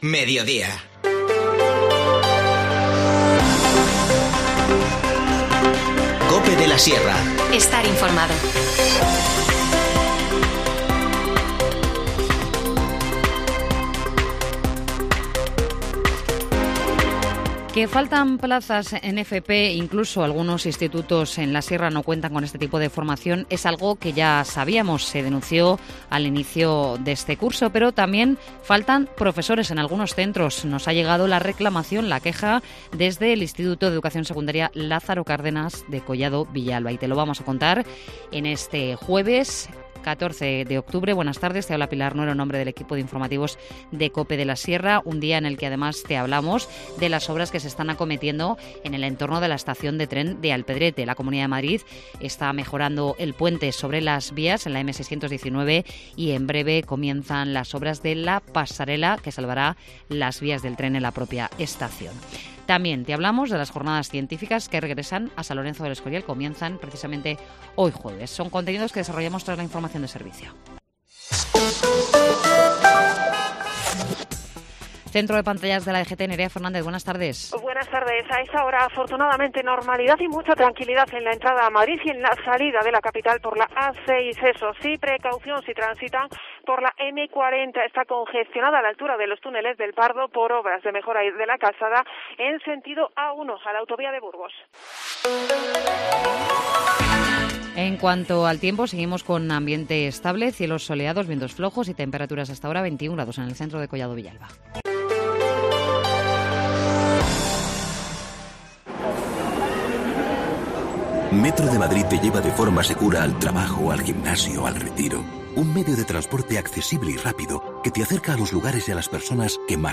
Informativo Mediodía 14 octubre